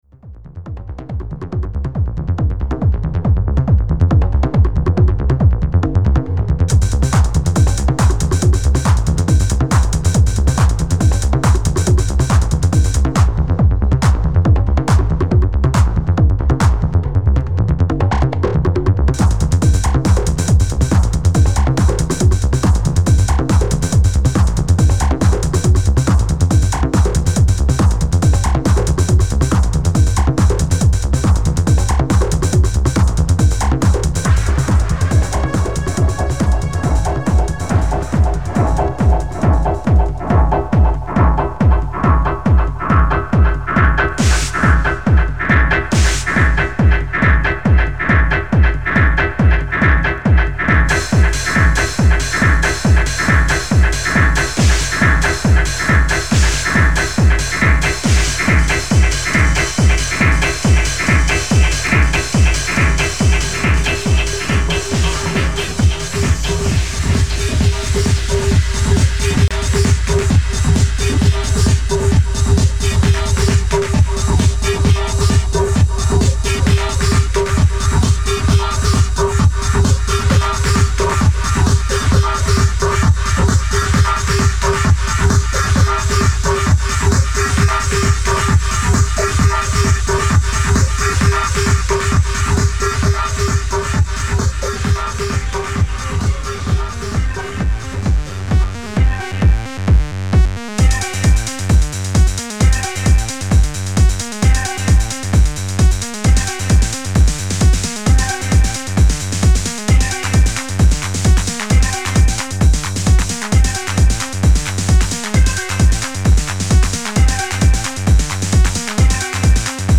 In: techno